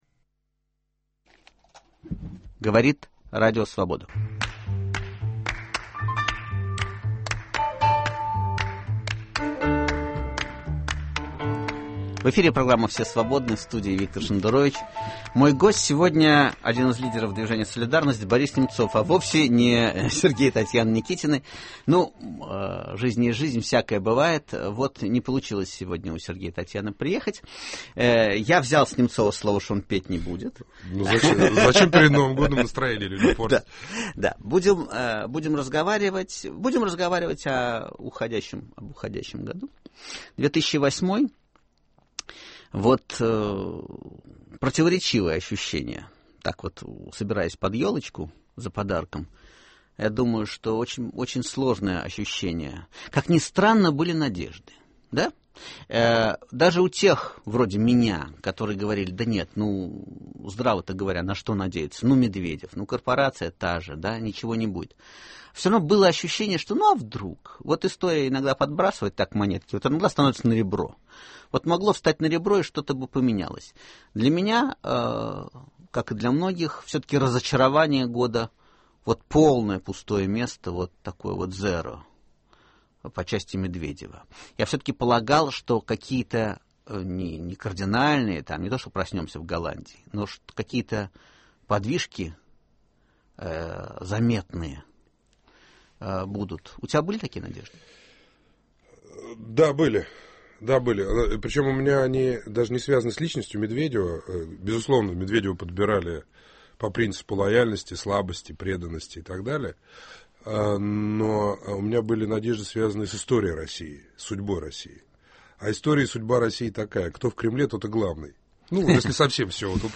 В гостях у Виктора Шендеровича – авторы и исполнители Сергей и Татьяна Никитины.